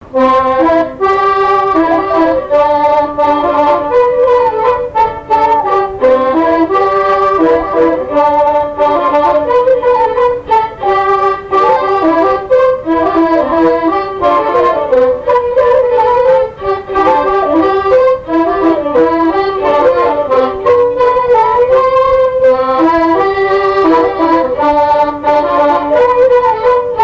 Chants brefs - A danser
danse : scottich trois pas
Pièce musicale inédite